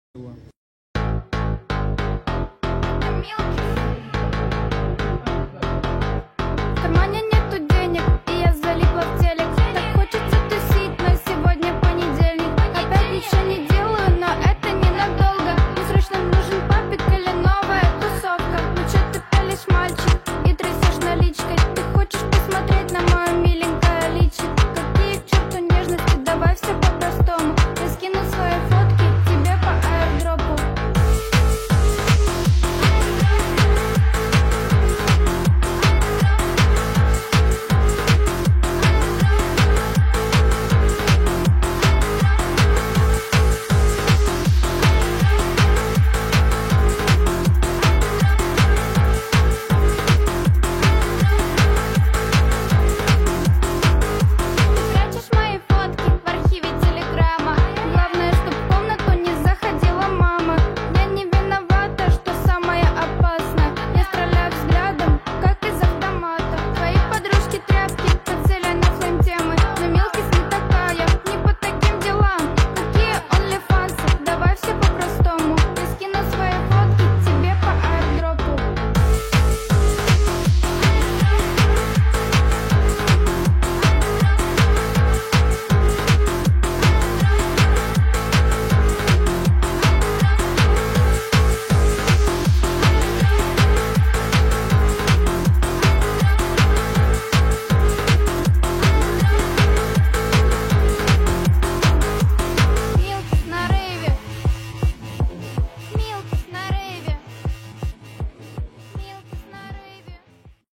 Кавер 2025 полная версия